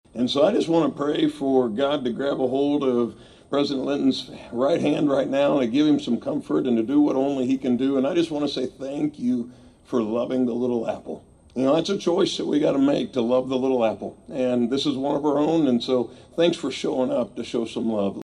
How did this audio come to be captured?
A community gathering was held outside Anderson Hall Tuesday evening as state, local and faith leaders came together in support of Kansas State University President Richard Linton, who earlier in the day announced his diagnosis of throat and tongue cancer.